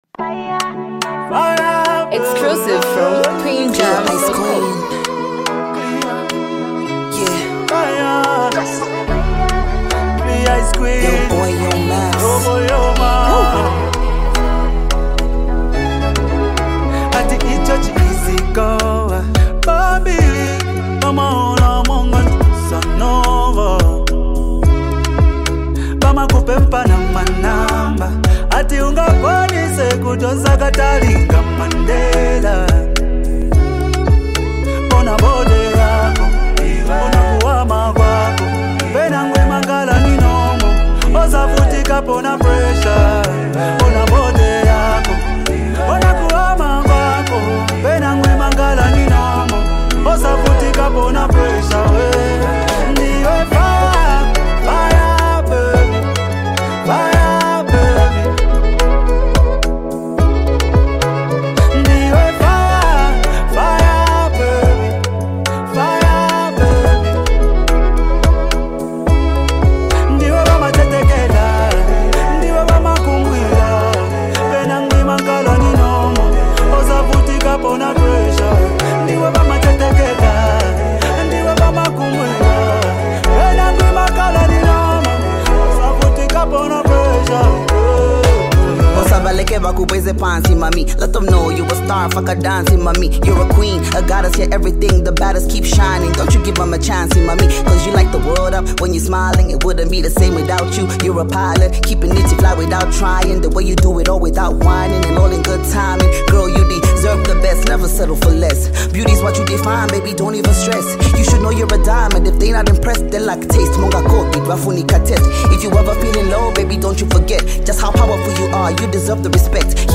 Highly multi talented act and super creative Female rapper